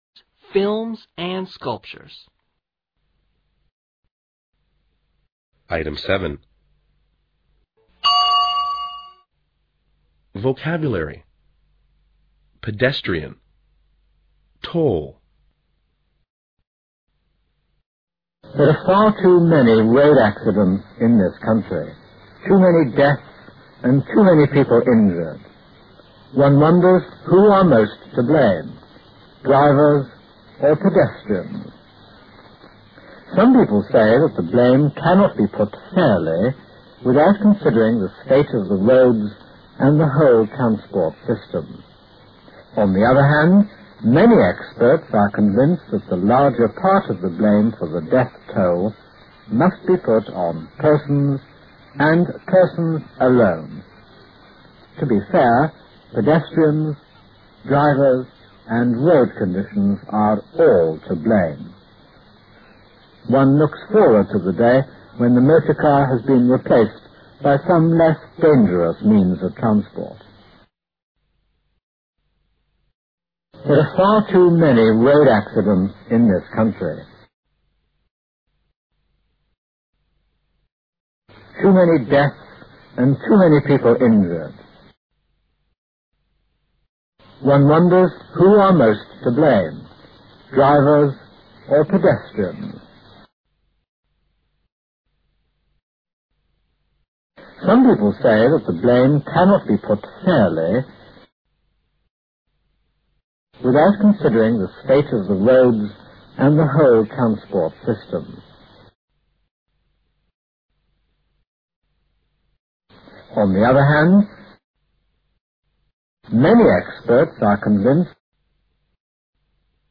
Step by Step 3000 第1册 Unit12:Dictation item(7)